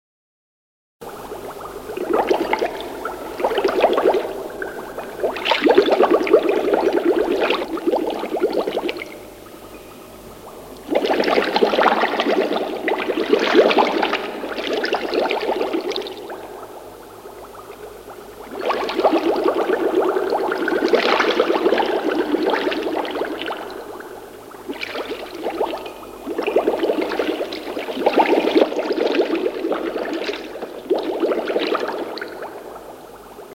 > Also erst aufhören, wenn ALLE Zellen blubbern :/ Das hoert sich dann etwa so an....
Blubber.mp3